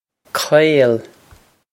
Pronunciation for how to say
qway-ol
This is an approximate phonetic pronunciation of the phrase.